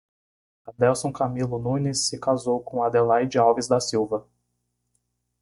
Read more a surname Frequency C2 Pronounced as (IPA) /ˈsiw.vɐ/ Etymology Derived from Silva (name of several places in Portugal), ultimately from silva (“forest”).